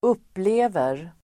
Uttal: [²'up:le:ver]